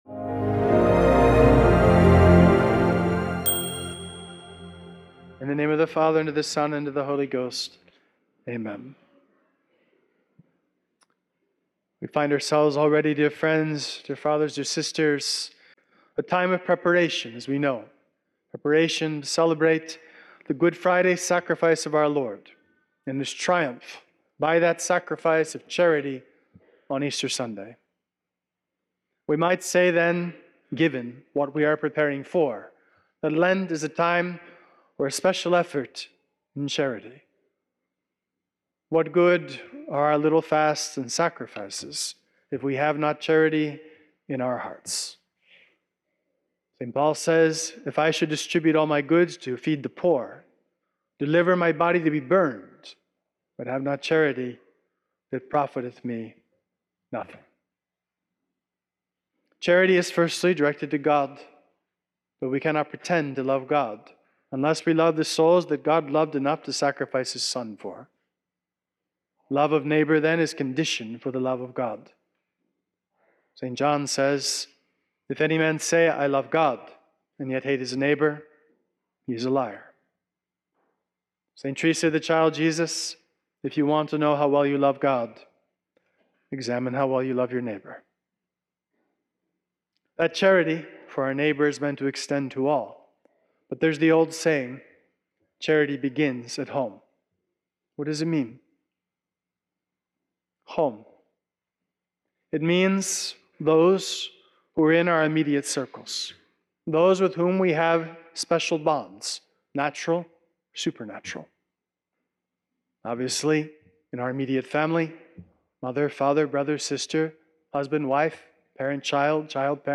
Sermon-232-Audio-converted.mp3